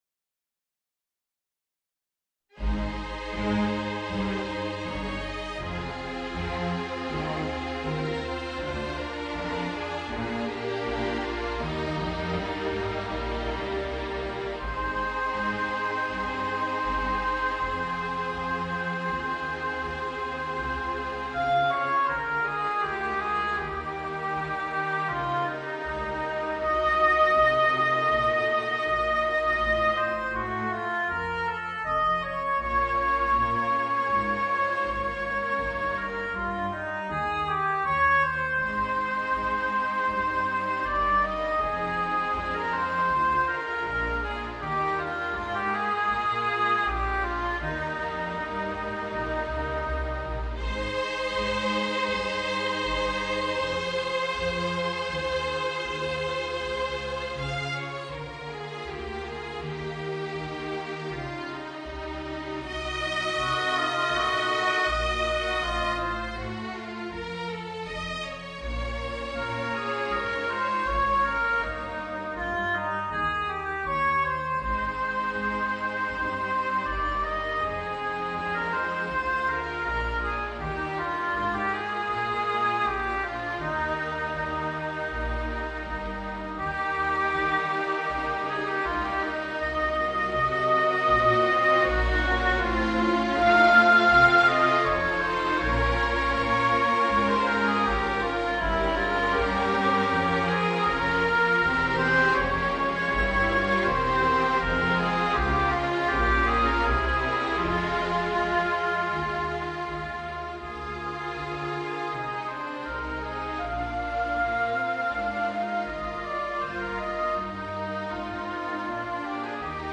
Voicing: Oboe and String Orchestra